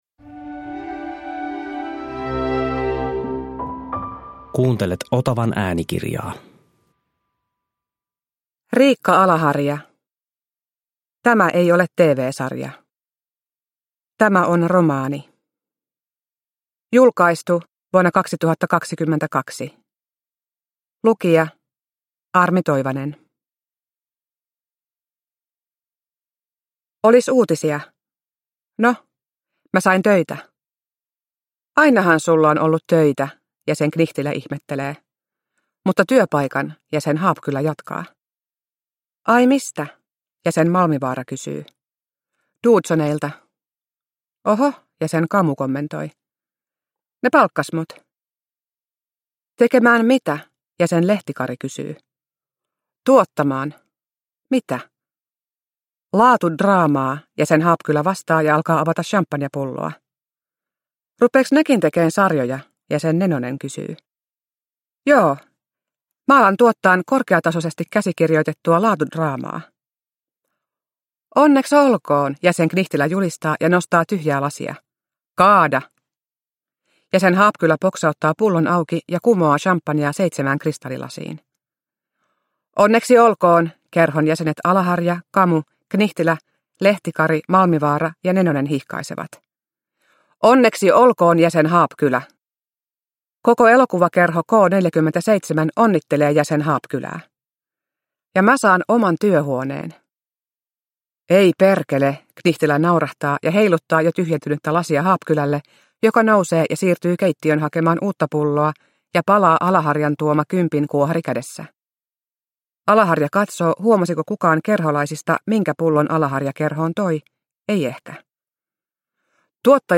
Tämä ei ole TV-sarja – Ljudbok – Laddas ner